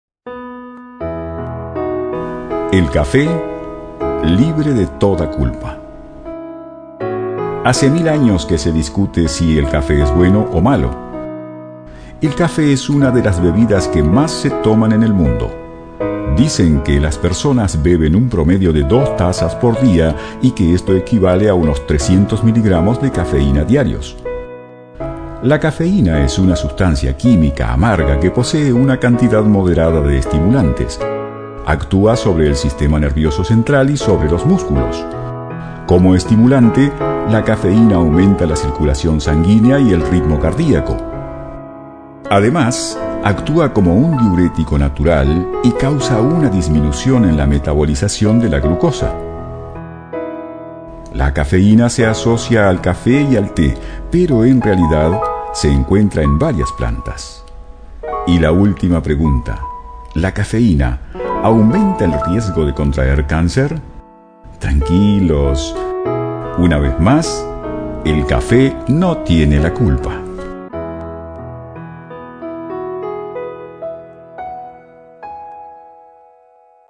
Sprecher spanisch (Südamerika). Dicción clara, firme, segura. Especial narraciones en off o audiolibros.
Sprechprobe: eLearning (Muttersprache):